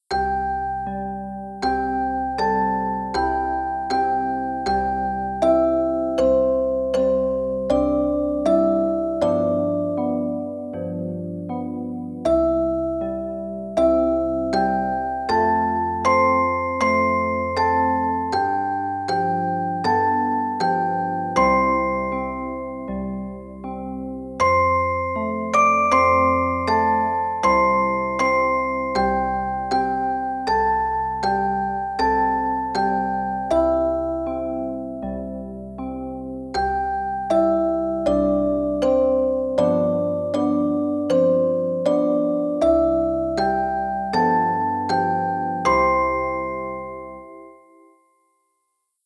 動作確認放送
毎日の夕方（午後5時）「夕焼け小焼け」